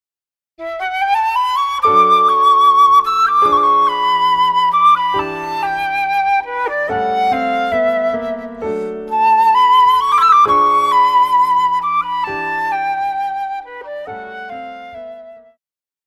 古典,流行
長笛
鋼琴
演奏曲
世界音樂
僅伴奏
沒有主奏
沒有節拍器